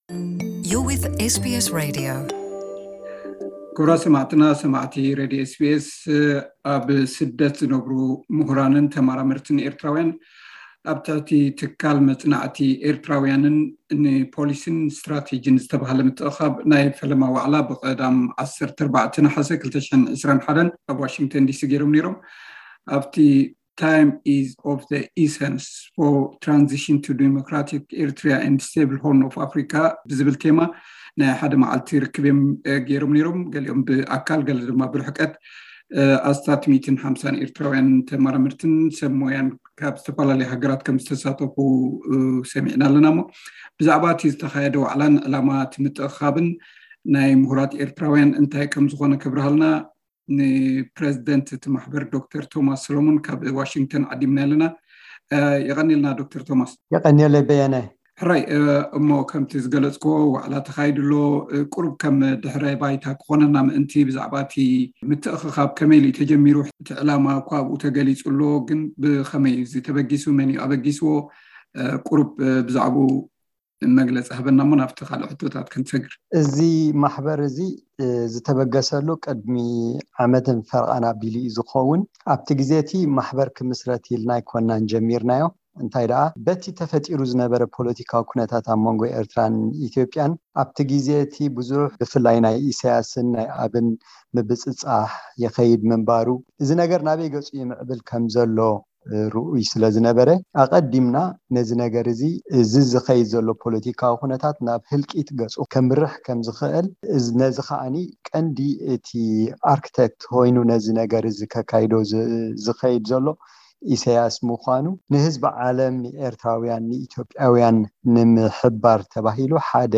ካብ ዋሺንግተን ዓዲምናዮ ዝገበርናዮ ቃለ መሕትት።